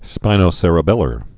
(spīnō-sĕrə-bĕlər)